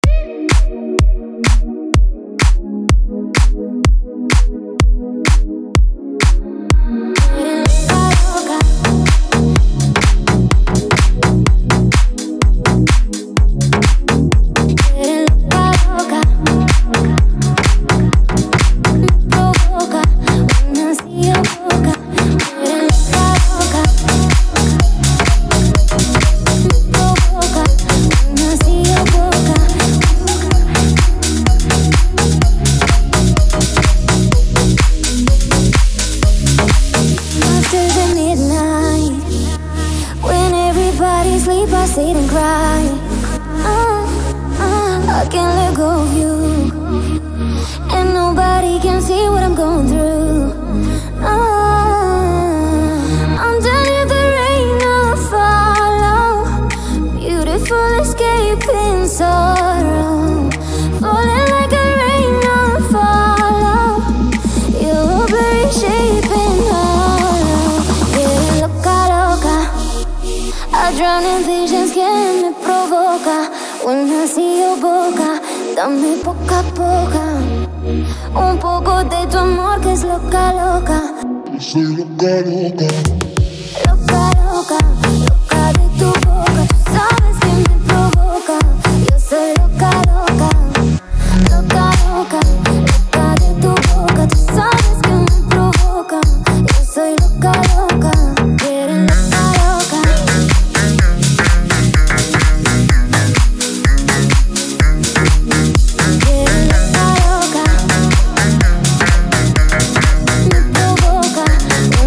Categoria: House Music